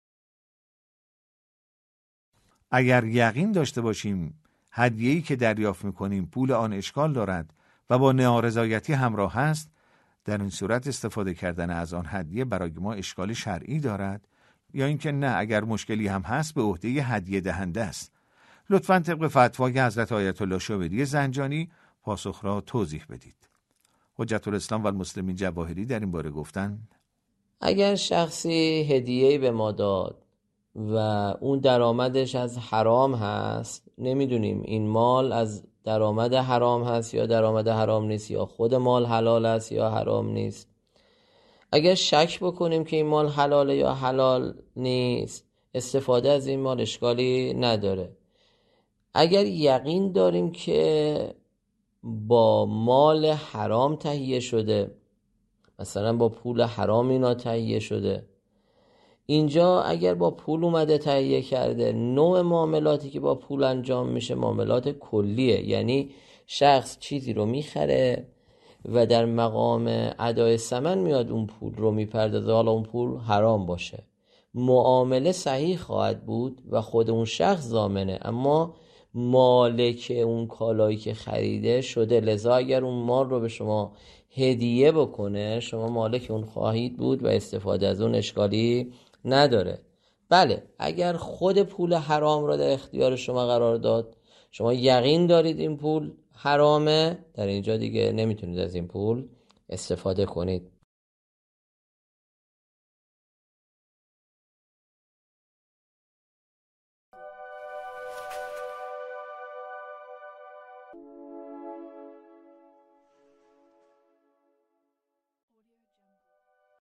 پرسش و پاسخی فقهی